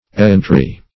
Errantry \Er"rant*ry\, n.